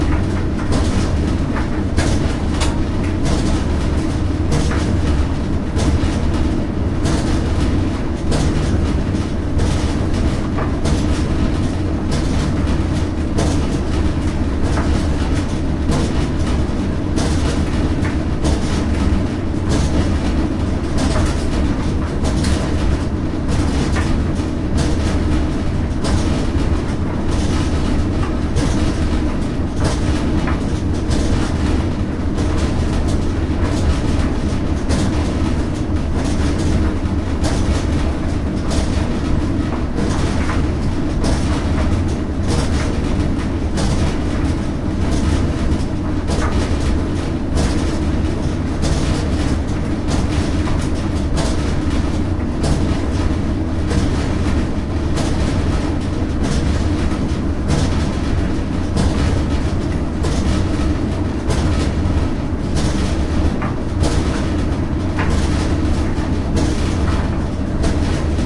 电器、技术和机电设备 " 杂物室的烘干机
描述：在一个封闭的小杂物间里对干衣机的录音。用Zoom H1 Handy Recorder录制。从6dB的峰值归一到0dB。
Tag: 衣服 干燥机 现场记录 房间 效用